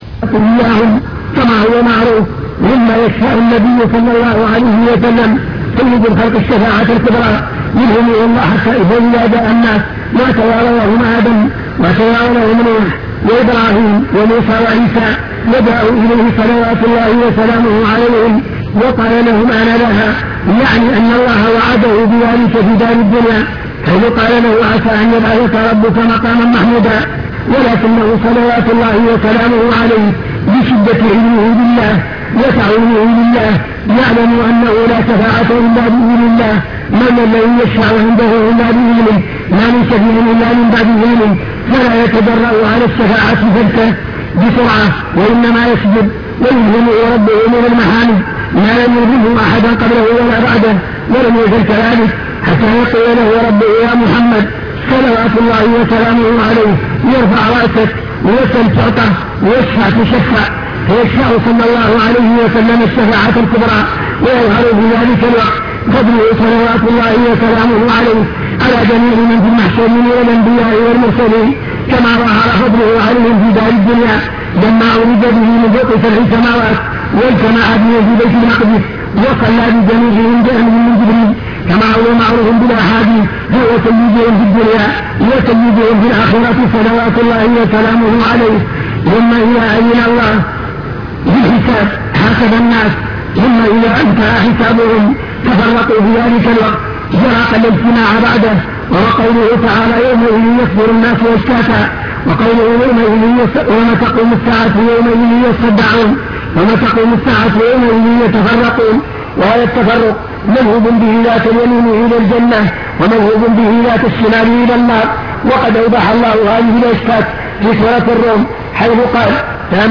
المكتبة الصوتية  تسجيلات - محاضرات ودروس  محاضرة الإيمان باليوم الآخر أمور غيبية يجب الإيمان بها